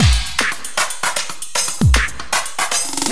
LOOP30--01-L.wav